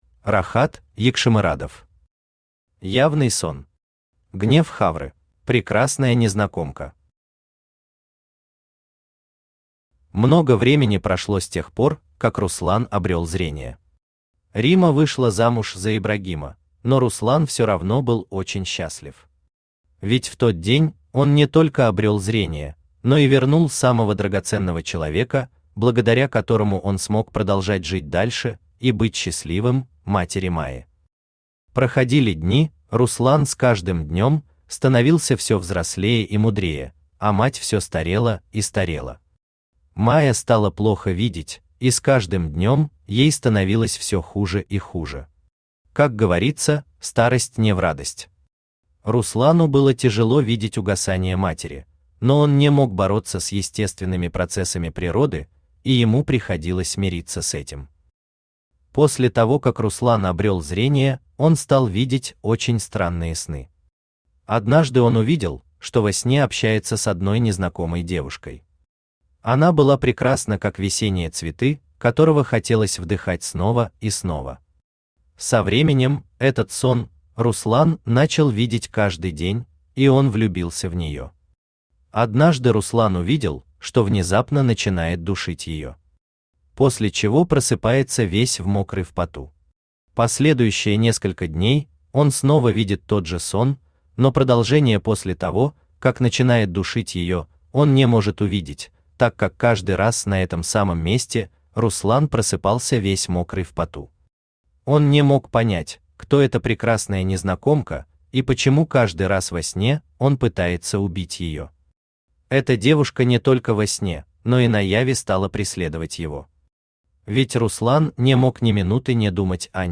Аудиокнига Явный сон. Гнев Хавры | Библиотека аудиокниг
Гнев Хавры Автор Рахат Хошгельдыевич Ягшимырадов Читает аудиокнигу Авточтец ЛитРес.